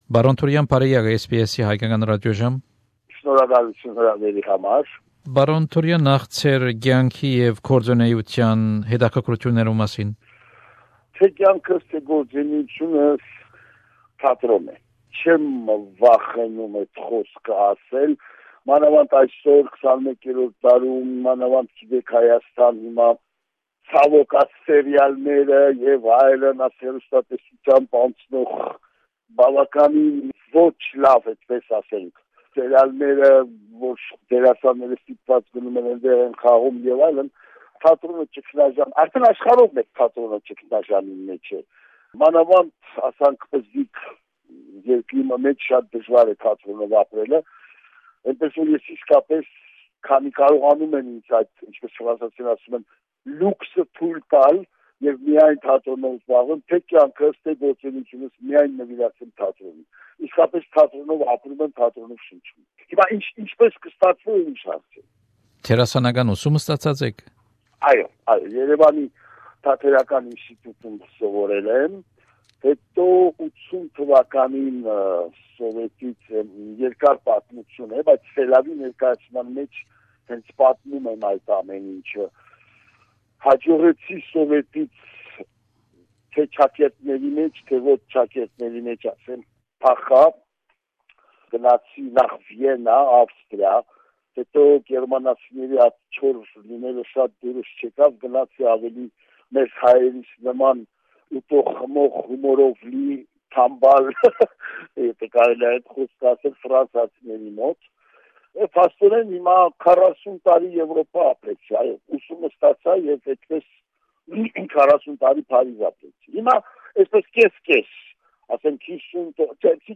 Հարցազրոյց